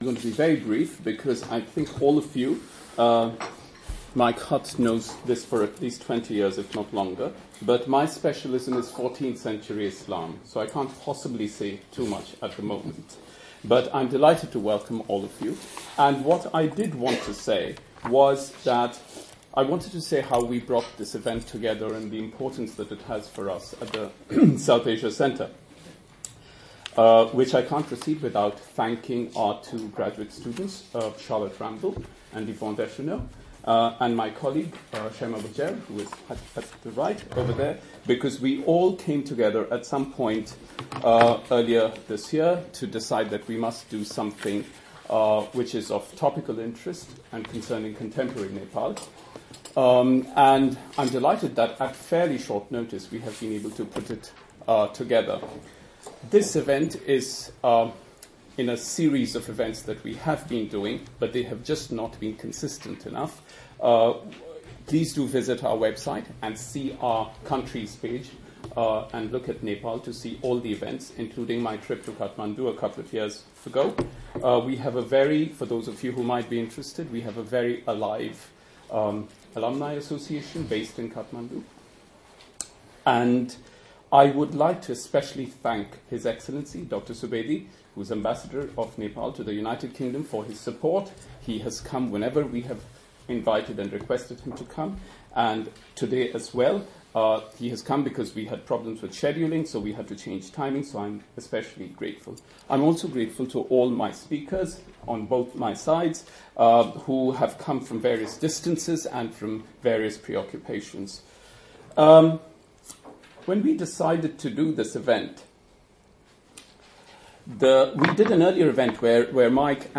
Roundtable Discussion
Opening Remarks: H.E. Dr Durga Bahadur Subedi